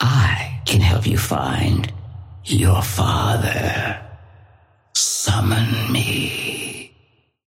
Patron_female_ally_wraith_start_02.mp3